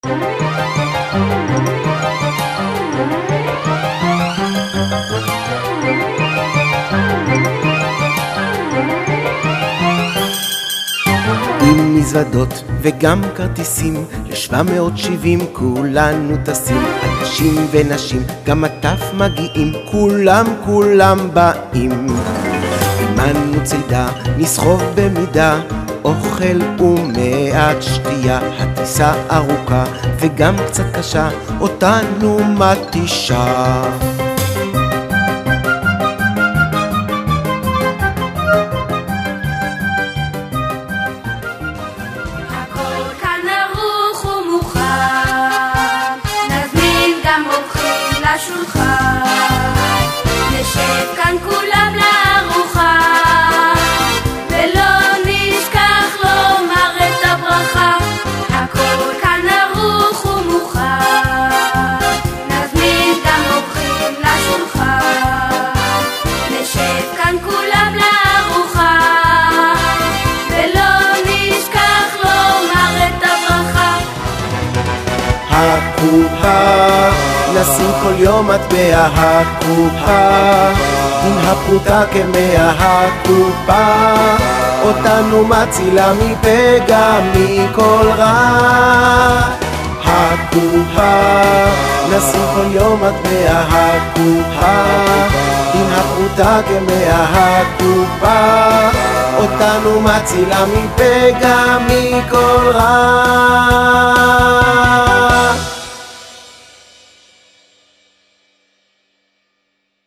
בימים אלו יצאה לאור מסיבה לילדים בלחני ניגוני חב"ד בלבד.